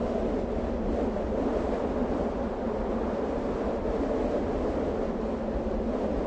target_wind_float_loop.ogg